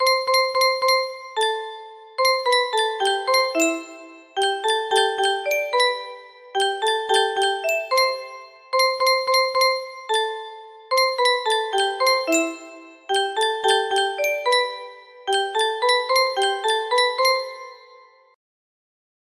Playmates music box melody